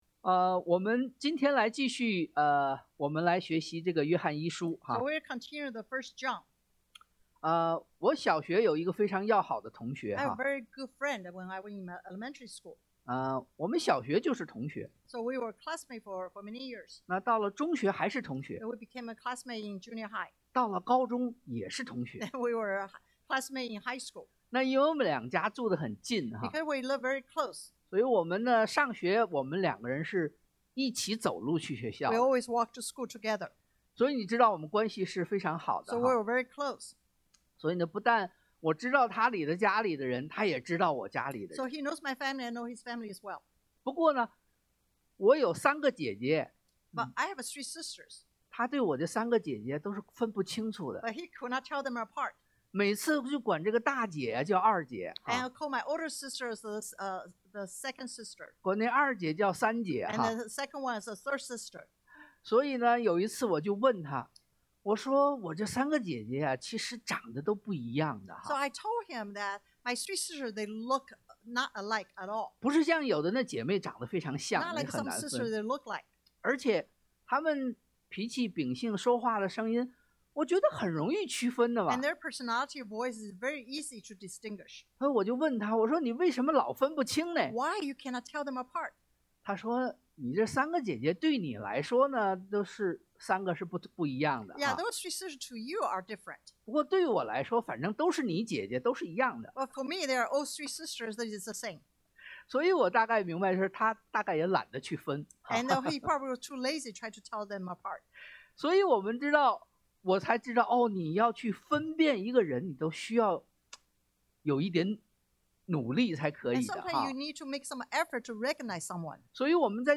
約壹1 John 2:12-27 Service Type: Sunday AM 1.